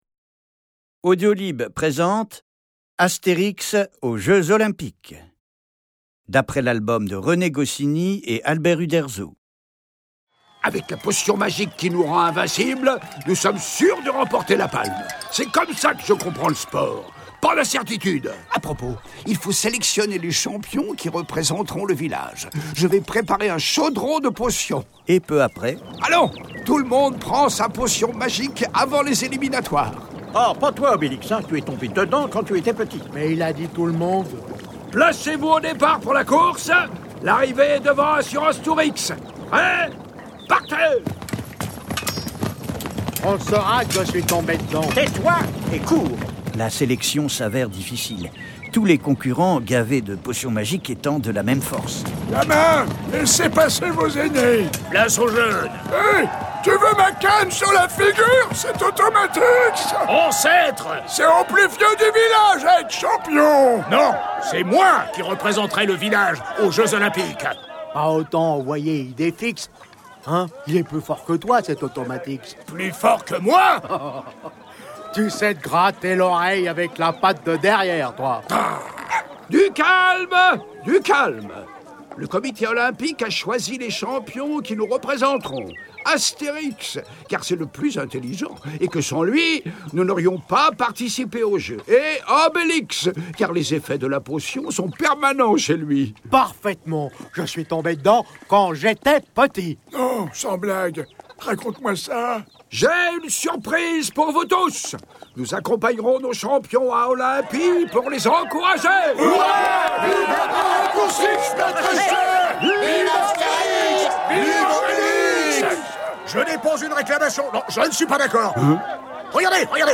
Diffusion distribution ebook et livre audio - Catalogue livres numériques
8 voix exceptionnelles, une musique originale et des bruitages sur-mesure, pour faire le plein d’aventures renversantes avec Astérix et Obélix.